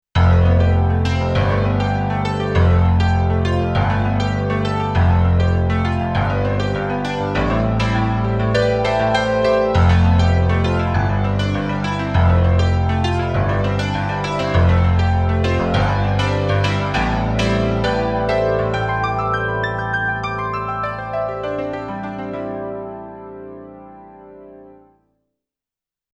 The CD insert has the message "All of the multi-Patch examples and songs were one realtime Performance on an XV-5080. No audio overdubbing was used to exceed the realtime capability of the unit."
22-SA-Piano-1.mp3